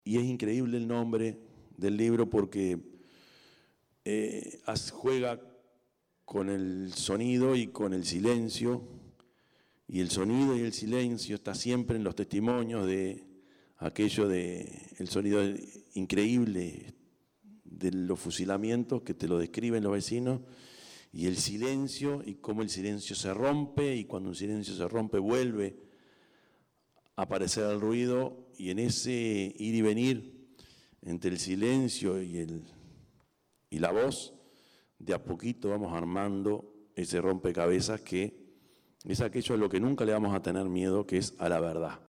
Se presentó el libro Silencio roto sobre los fusilados de Soca en el Complejo Cultural Politeama
yamandu_orsi-_intendente_de_canelones.mp3